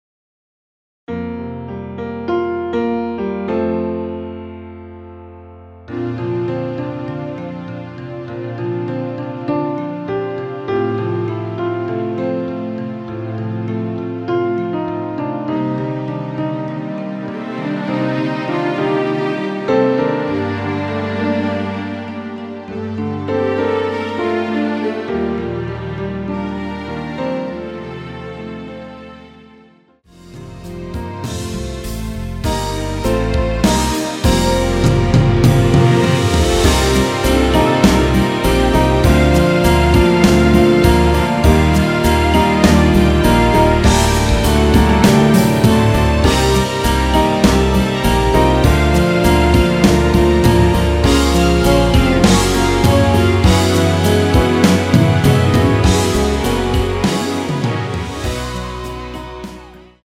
전주 없이 시작 하는 곡이라 전주 만들어 놓았습니다.(미리듣기참조)
원키에서(-2)내린 MR입니다.
Bb
앞부분30초, 뒷부분30초씩 편집해서 올려 드리고 있습니다.
중간에 음이 끈어지고 다시 나오는 이유는